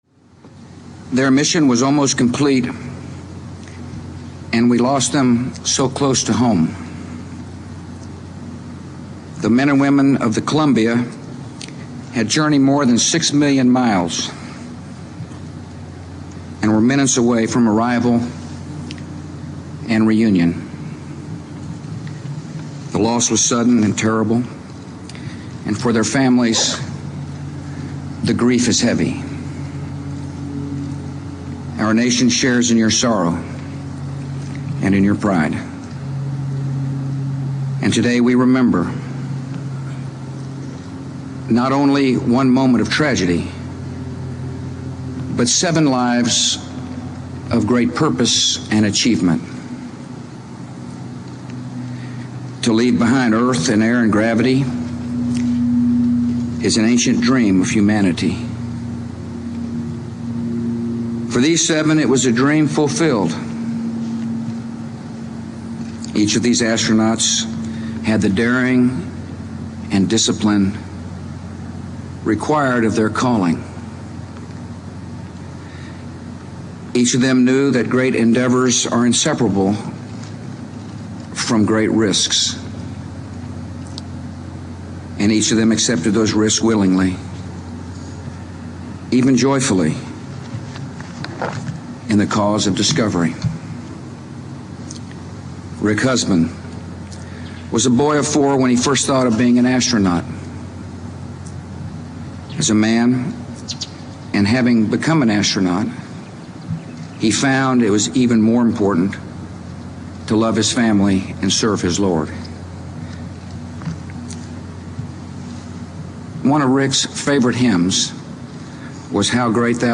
American Rhetoric: George W. Bush: Speech at the Memorial Service for Space Shuttle Columbia Astronauts